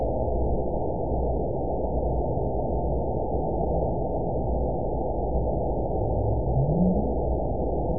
event 911877 date 03/11/22 time 04:25:30 GMT (3 years, 9 months ago) score 9.48 location TSS-AB04 detected by nrw target species NRW annotations +NRW Spectrogram: Frequency (kHz) vs. Time (s) audio not available .wav